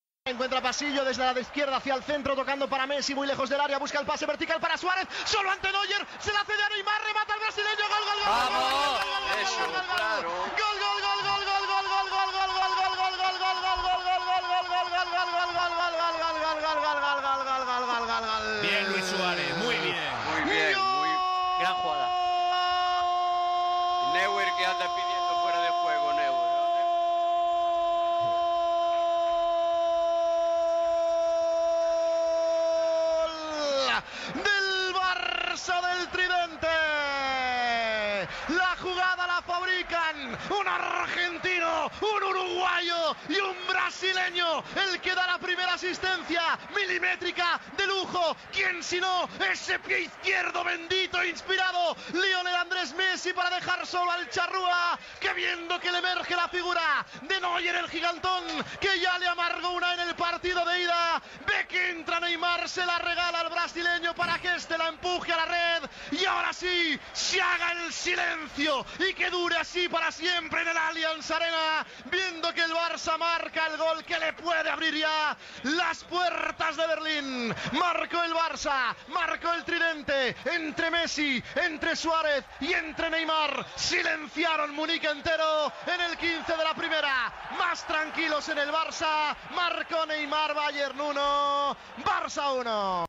Transmissió del partit de tornada de la fase eliminatòria de la Copa d'Europa de futbol masculí entre el Bayern München i el Futbol Club Barcelona.
Narració del primer gol del Futbol Club Barcelona, marcat per Neymar. Ambient al camp.
Esportiu